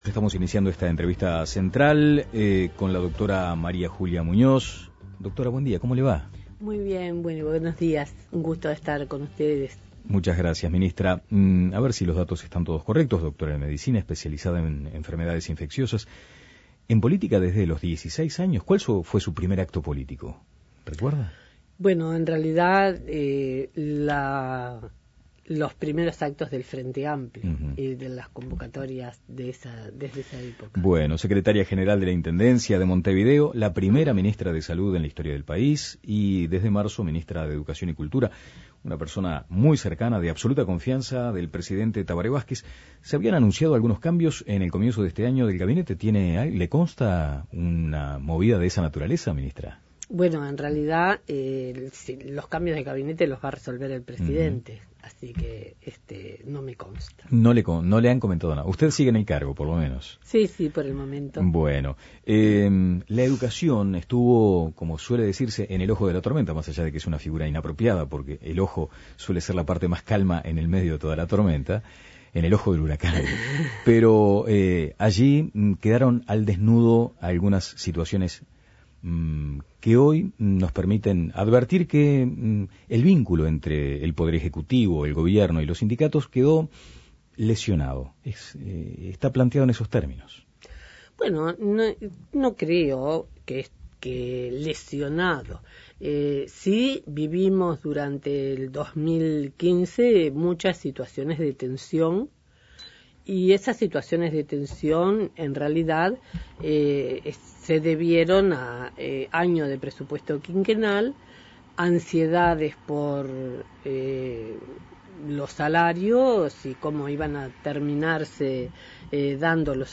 Entrevista a María julia Muñoz